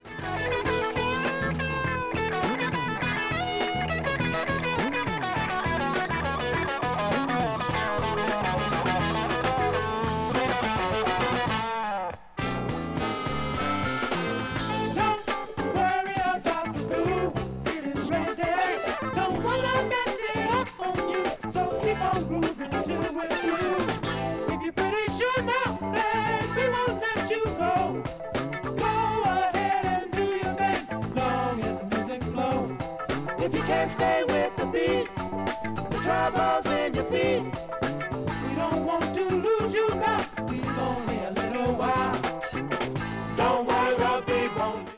Funk / Soul